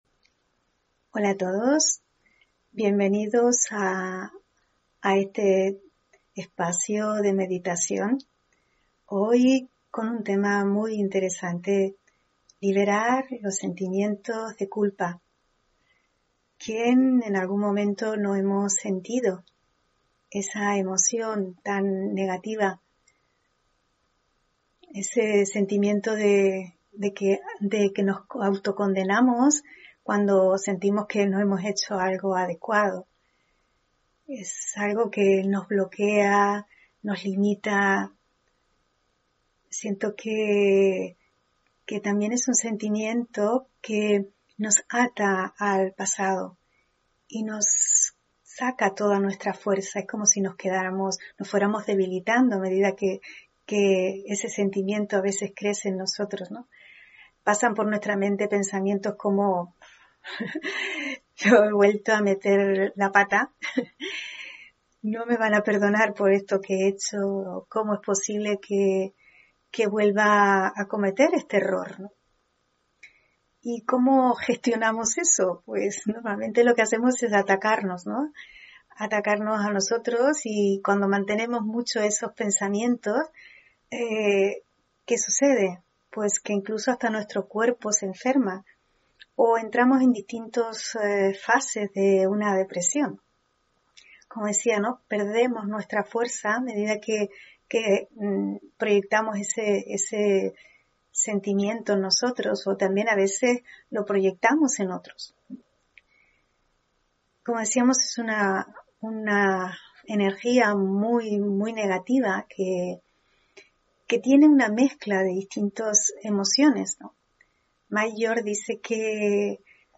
Meditación y conferencia: Liberar los sentimientos de culpa (17 Febrero 2022)